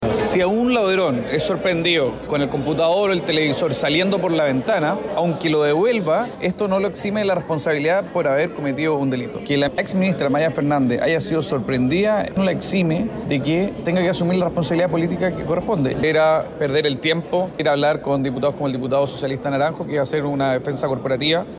“Era perder el tiempo ir a habalr con diputados como el diputado socialista Naranjo que iba a hacer una defensa corporativa”, señaló el diputado Cristián Araya.